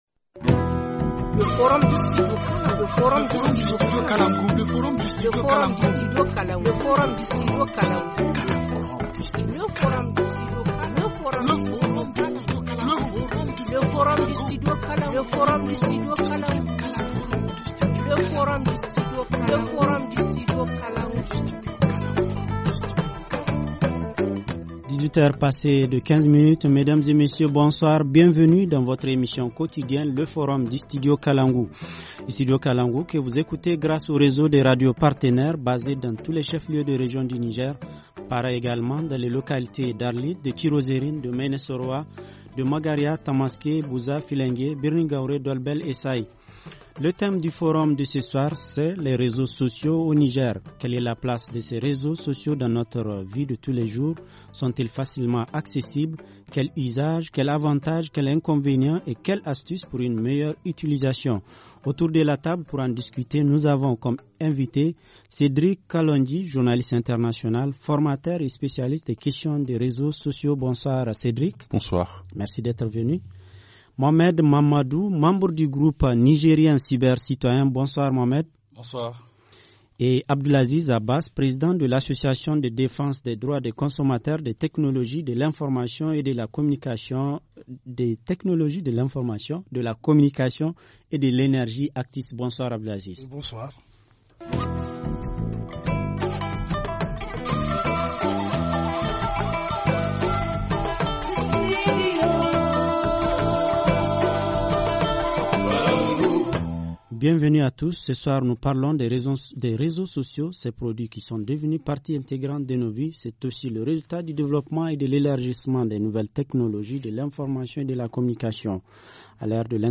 Forum du 16 février 2017:Les réseaux sociaux au Niger : accessibilité, usages, avantages et inconvénients.